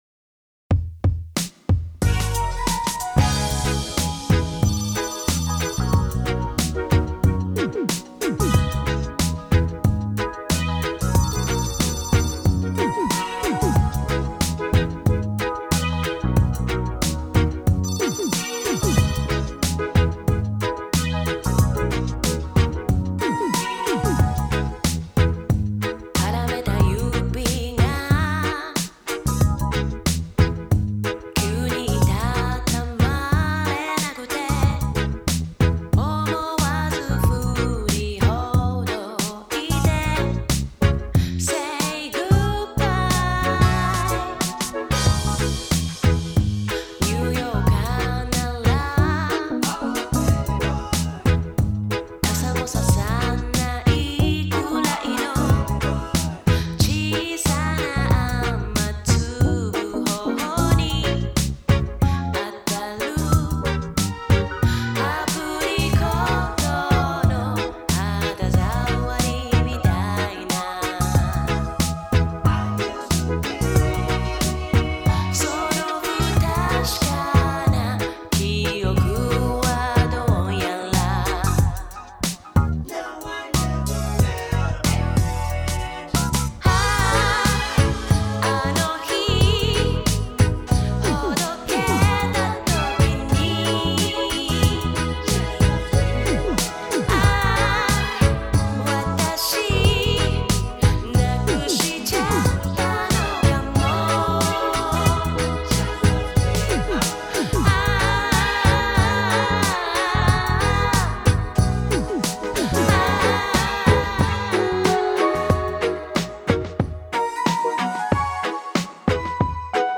〈LOVERS ROCK〉を体現し続け幅広い世代から絶大な支持を受けるシンガー